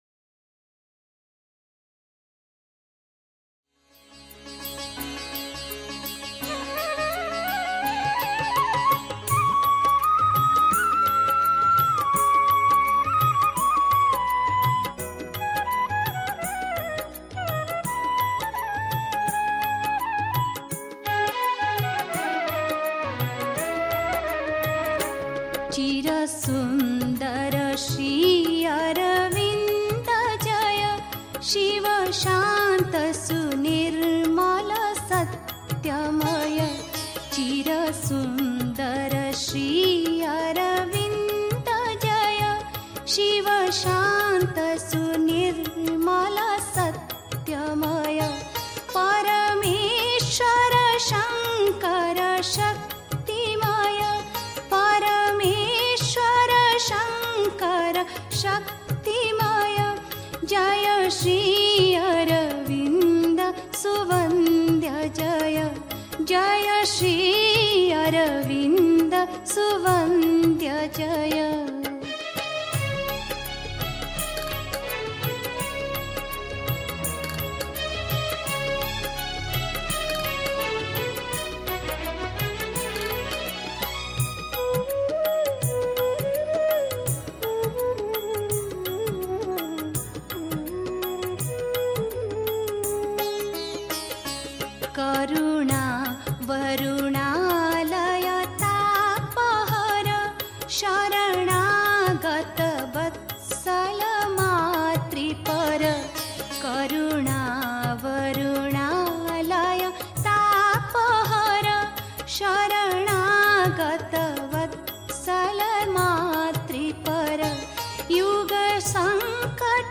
1. Einstimmung mit Musik. 2. Die Formulierung unseres angestrebten Ideals (Sri Aurobindo, CWSA, Vol. 13, p. 536) 3. Zwölf Minuten Stille.